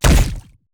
impact_projectile_005.wav